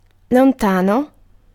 Ääntäminen
IPA : /ˈdɪstənt/